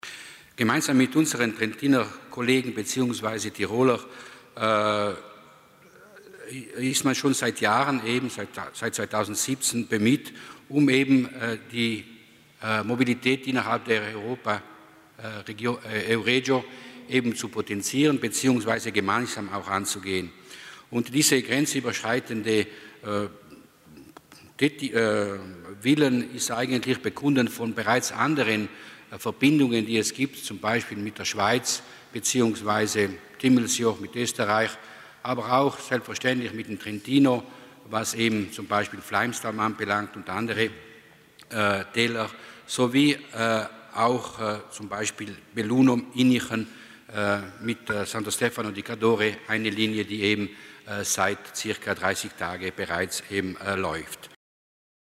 Landesrat Mussner zu den Neuheiten im Zugverkehr